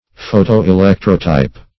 Search Result for " photo-electrotype" : The Collaborative International Dictionary of English v.0.48: Photo-electrotype \Pho`to-e*lec"tro*type\, n. (Print.)
photo-electrotype.mp3